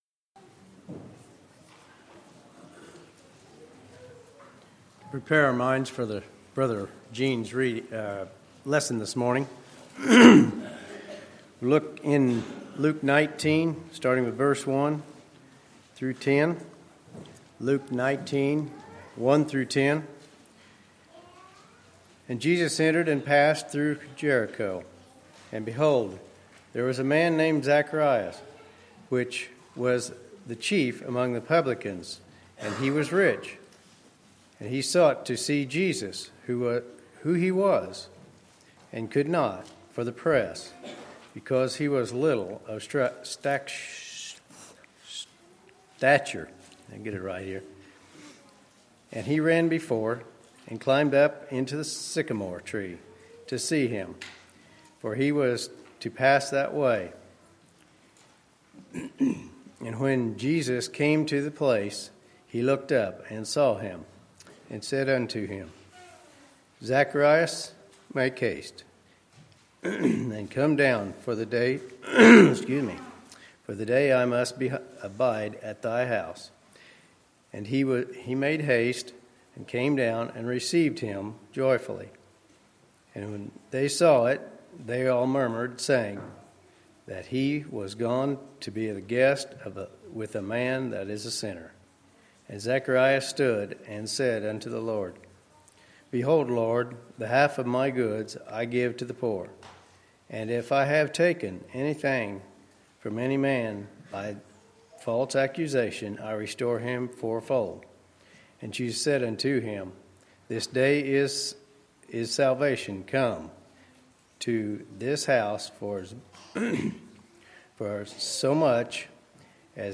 Sermons, December 11, 2016